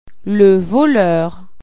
Voler   loo-ich
Voler   ph*lawn